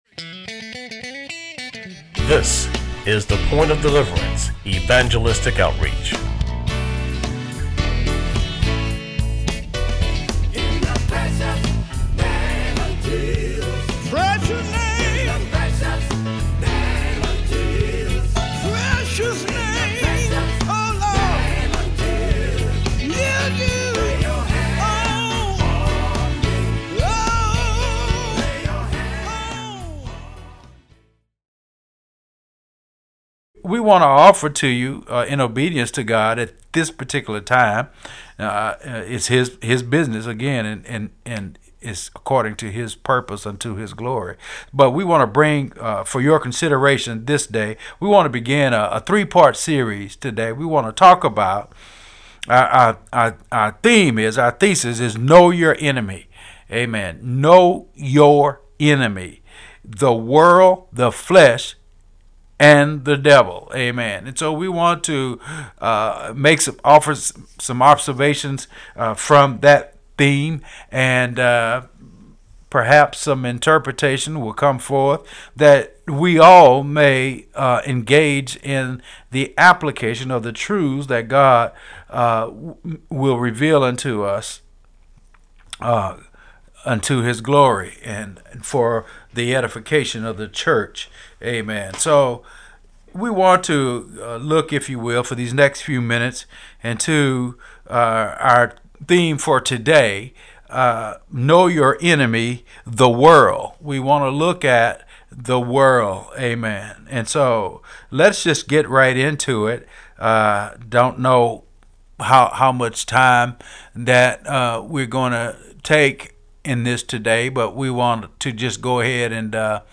Today we would endeavor to offer for your edification the first in a series of teachings and messages. The theme is ‘Know Your Enemy – The World, The Flesh, & The Devil.